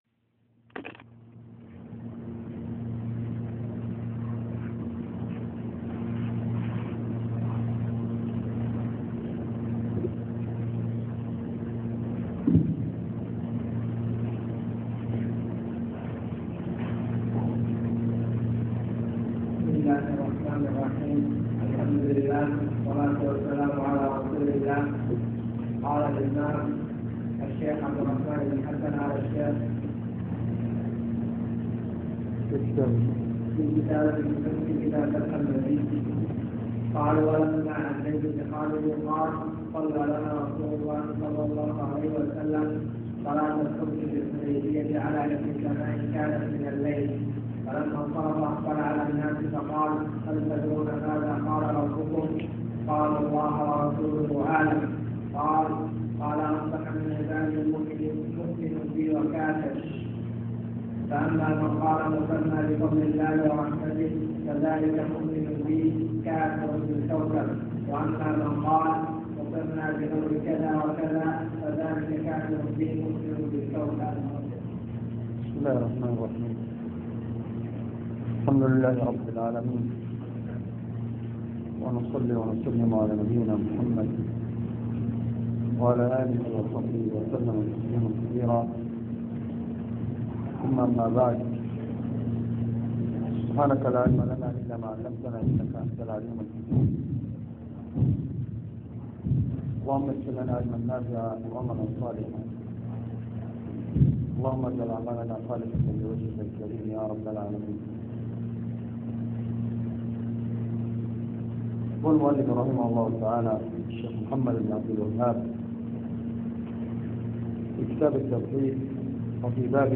تسجيل لدروس شرح كتاب فتح المجيد شرح كتاب التوحيد